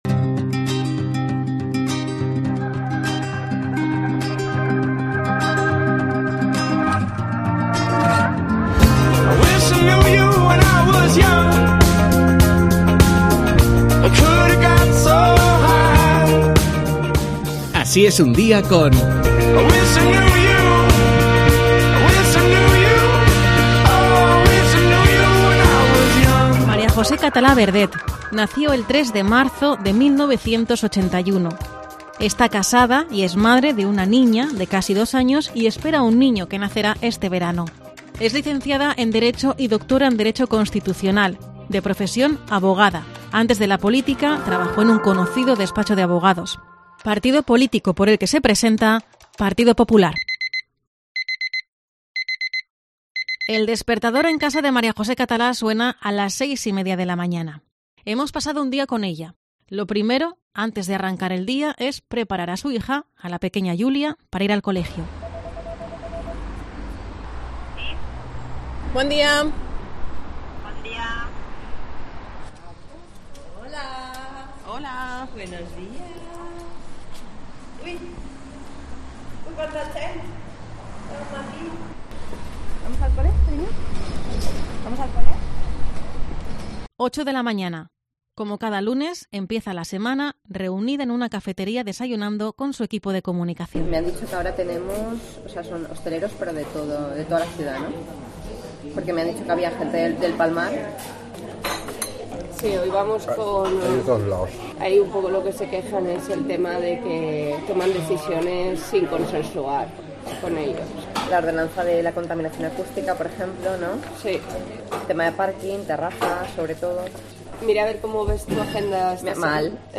Los micrófonos de COPE València han recogido los sonidos de cada momento.